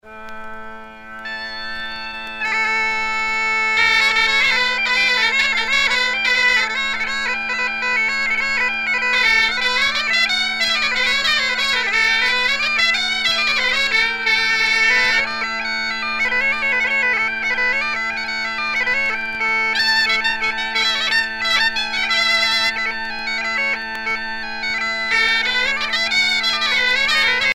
Usage d'après l'analyste gestuel : danse ;
Pièce musicale éditée